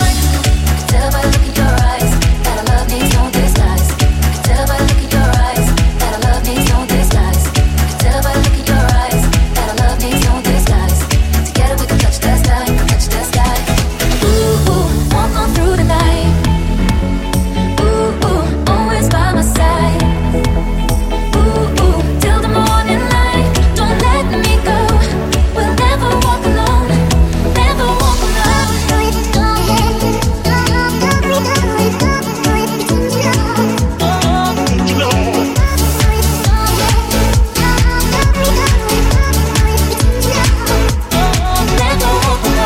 hit - nuove proposte - remix
Genere: pop, club, deep, remix